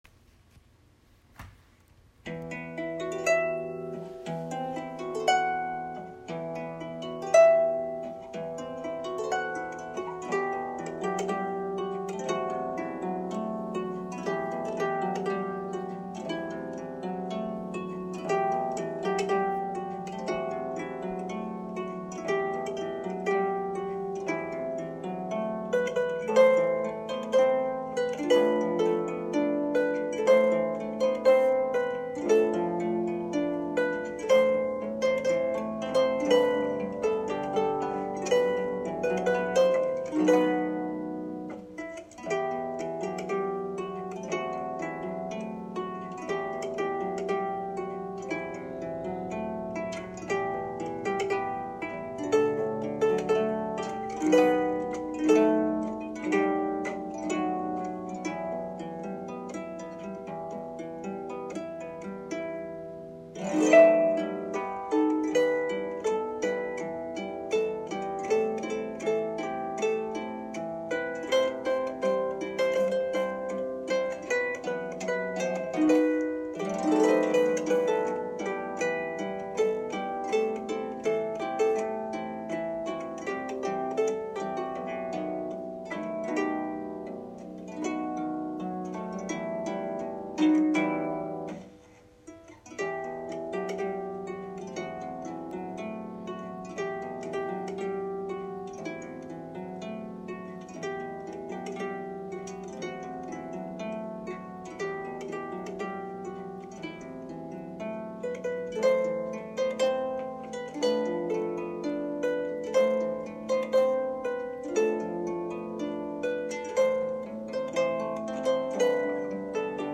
Not-‘Til-Then-Harp-recording.m4a